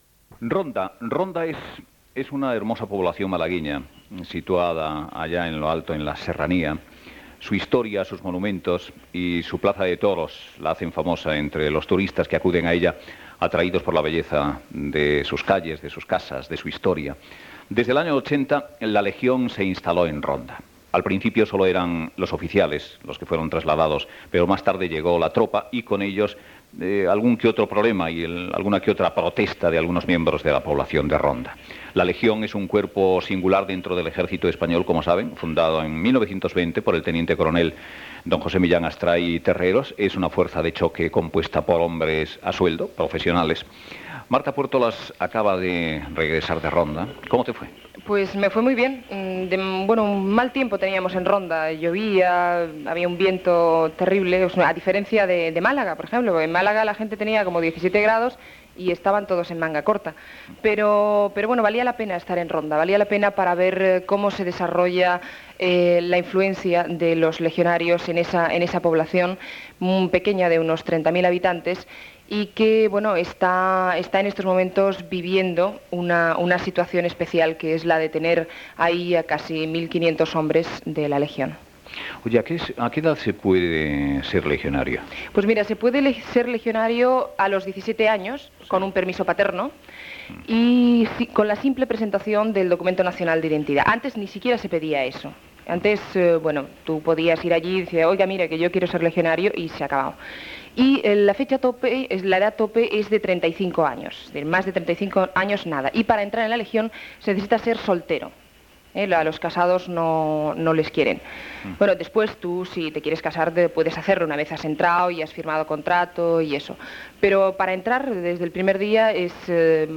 Reportatge fet a Ronda (Andalusia) sobre el quarter del Tercio Alejandro Farnesio de la Legión que tenia la seva seu en aquella localitat